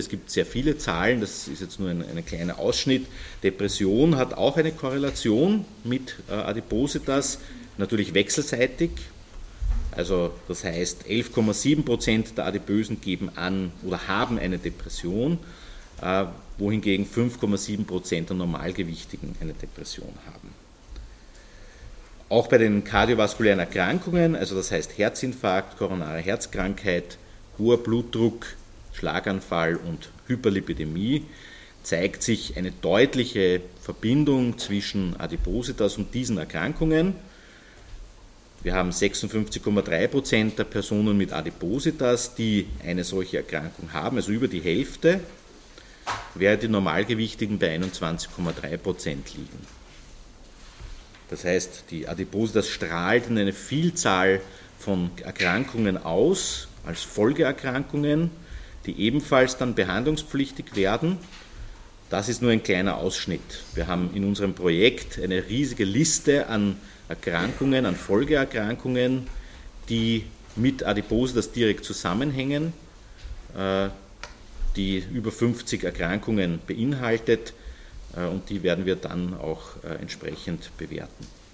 .mp3 O-Ton Dateien der Pressekonferenz vom 21.06.2022: